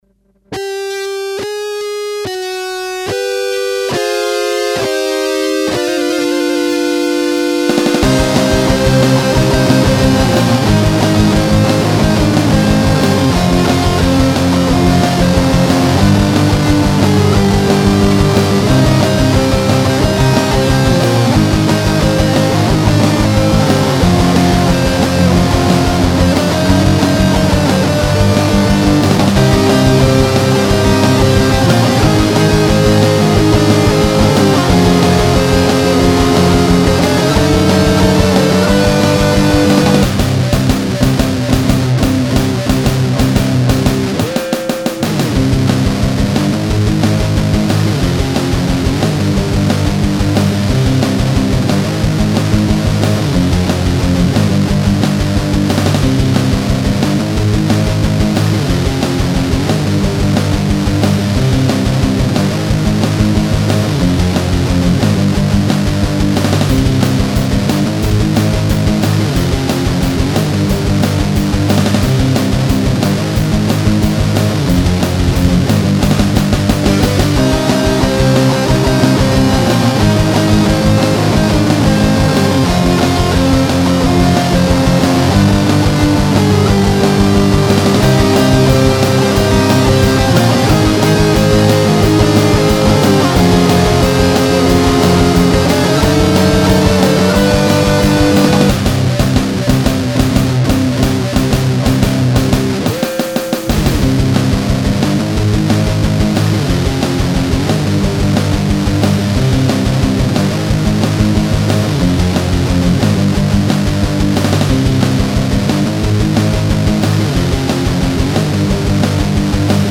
On peut avoir une version allongée pour rajouter un petit solo? :d
Par contre j'ai viré tout le solo (les 2 voix :o) alors tu te demerdes [:cupra]
NoSolo.mp3